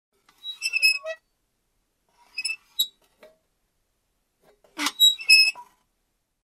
• Качество: высокое
Скрипучий звук качелей, терзающий наши уши